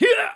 attack_1a.wav